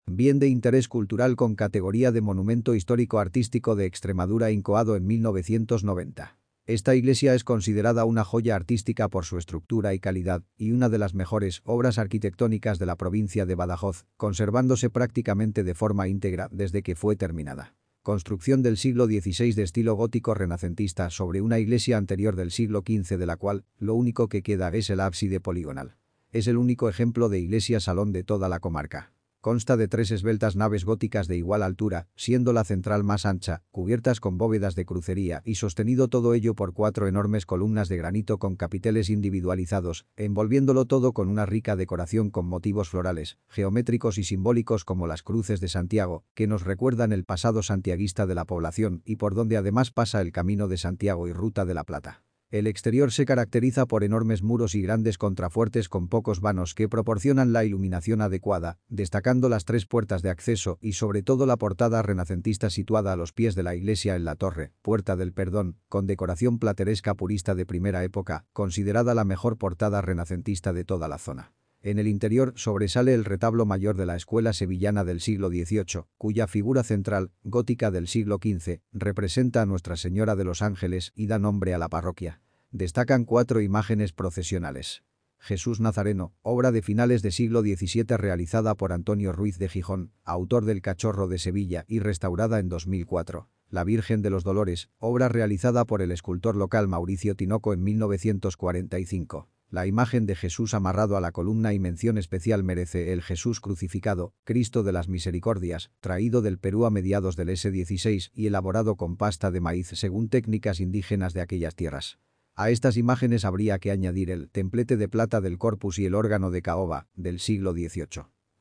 Audioguía